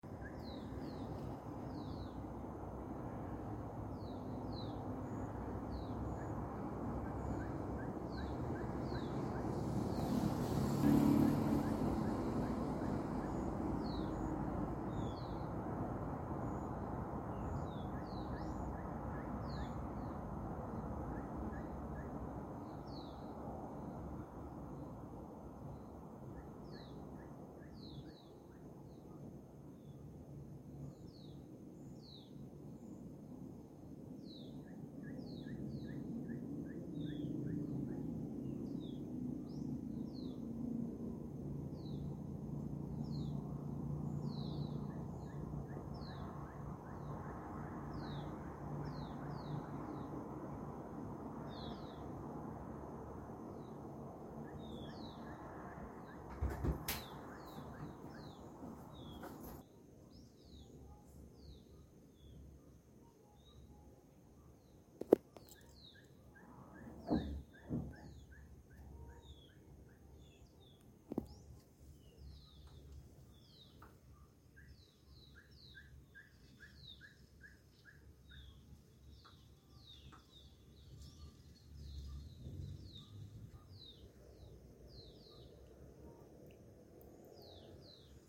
Ferruginous Pygmy Owl (Glaucidium brasilianum)
Country: Argentina
Province / Department: Tucumán
Condition: Wild
Certainty: Recorded vocal